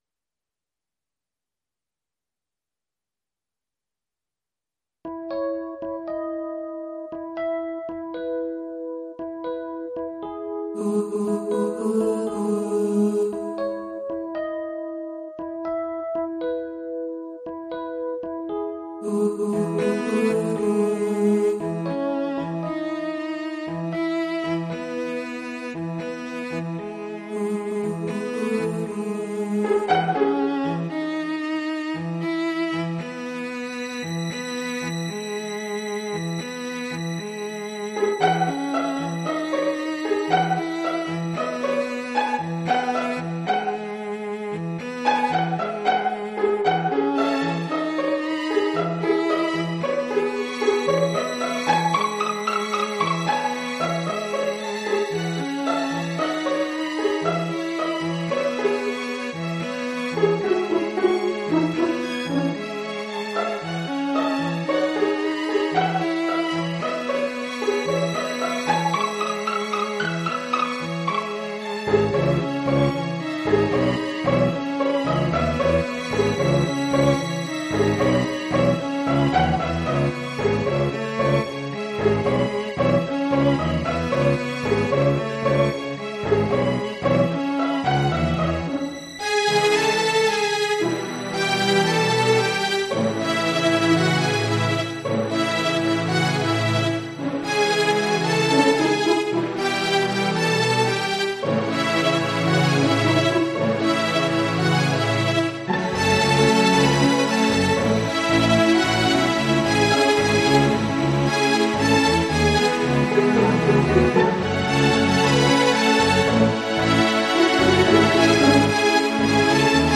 Professional musical transcription (backing track)